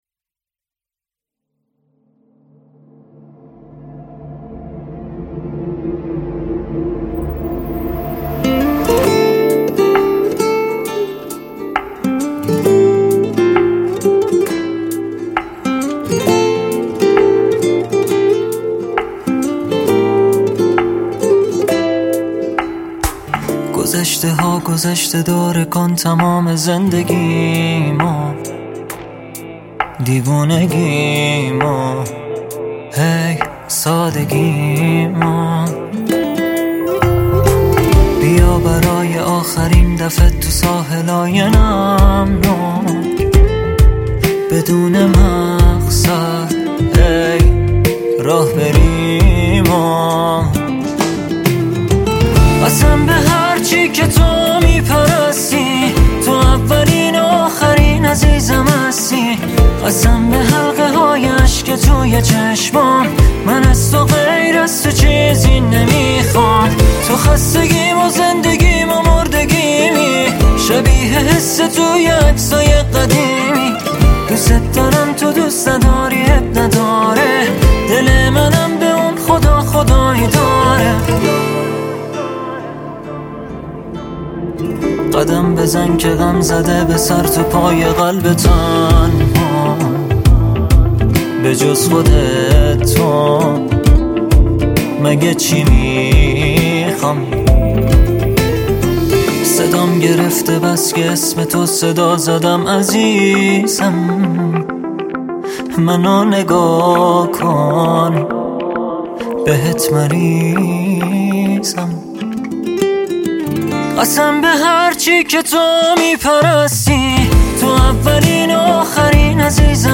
دانلود آهنگ شاد
گیتار